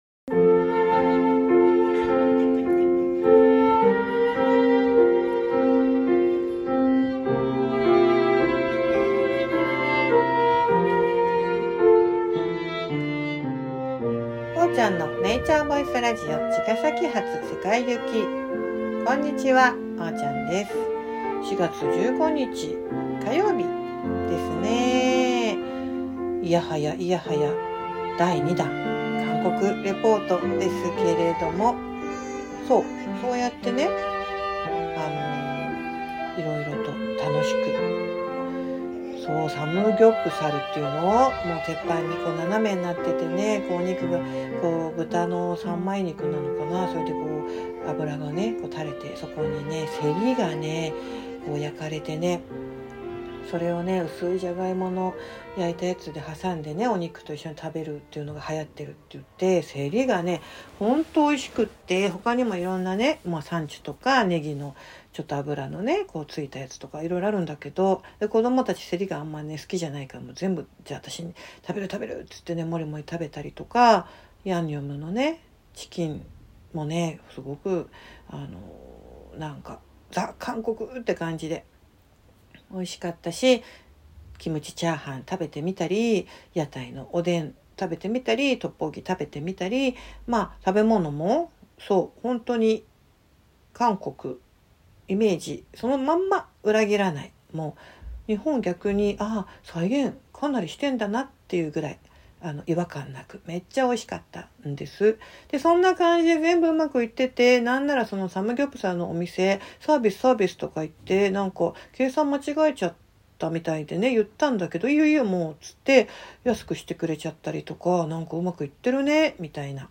ゆるゆるとおしゃべりしています。